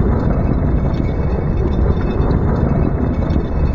Rumble.ogg